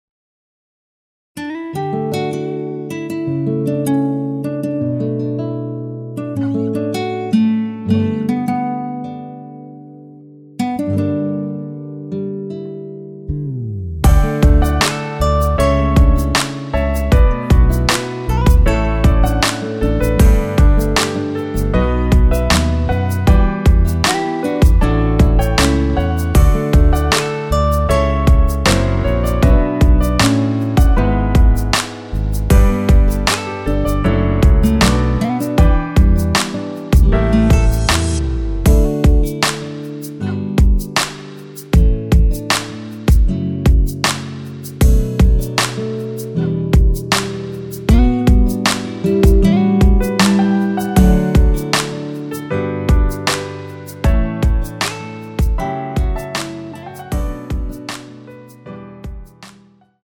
MR입니다.
원키에서(-2)내린 MR입니다.
앞부분30초, 뒷부분30초씩 편집해서 올려 드리고 있습니다.